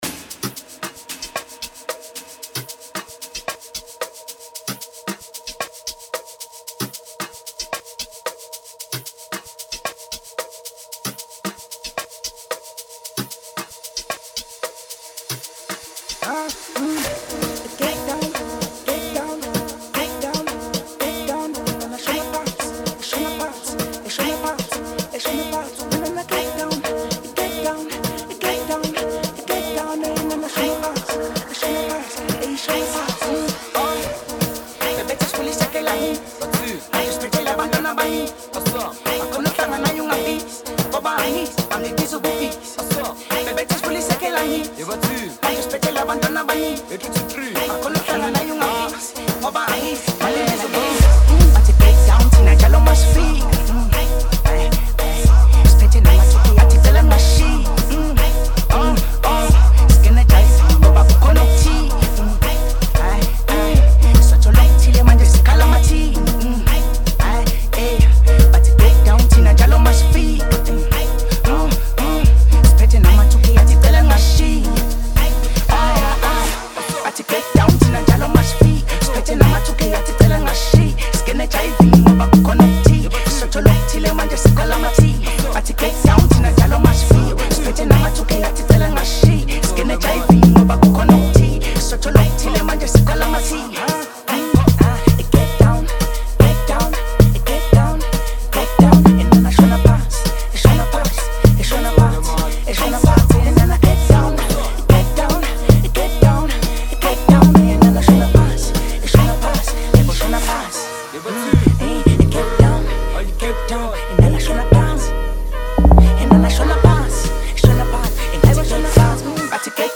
06:00 Genre : Amapiano Size